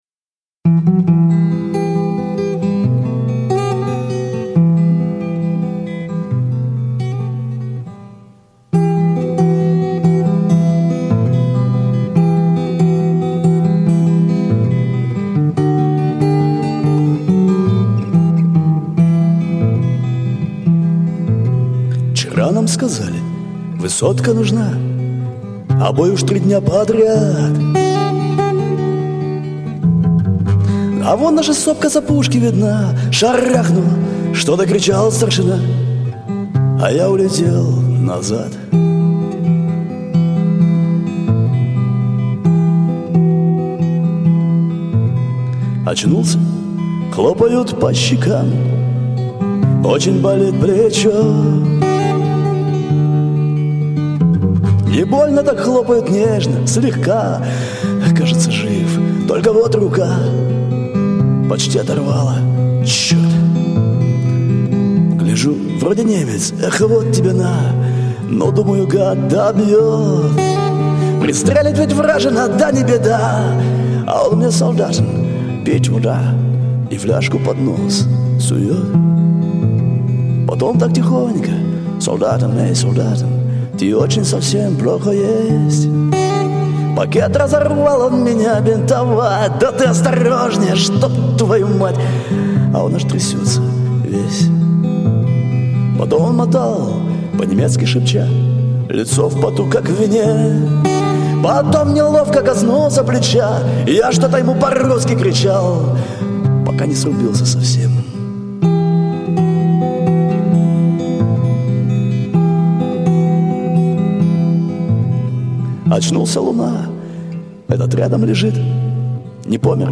Армейские под гитару
/ песни великой отечественной под гитару/